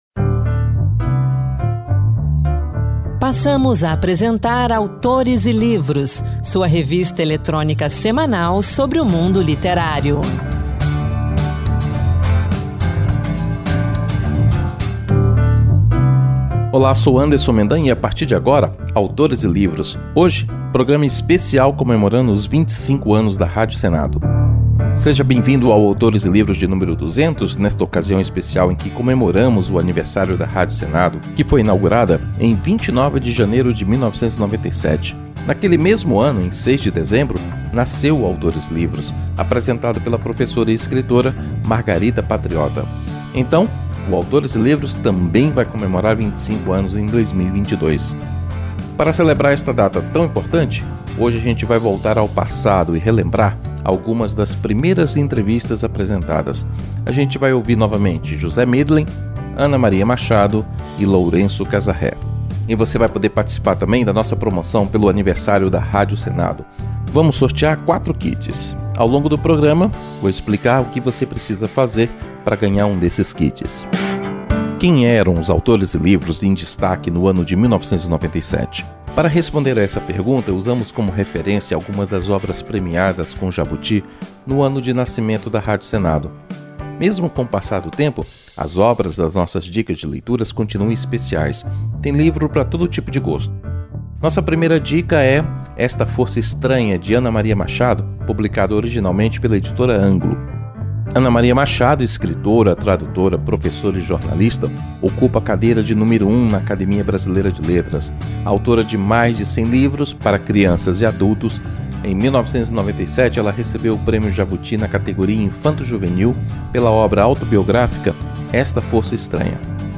Em comemoração aos 25 anos da Rádio Senado, o Autores e Livros desta semana viaja de volta à 1997 e traz trechos das entrevistas de José Mindlin